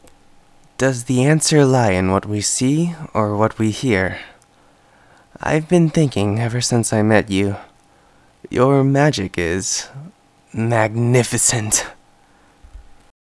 Voice Actor
We will be looking for a voice similar to the original.